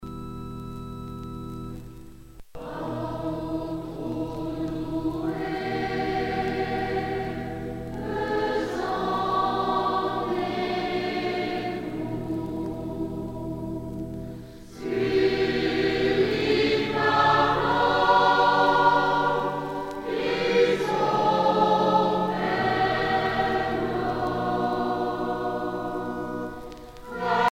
dévotion, religion
Pièce musicale éditée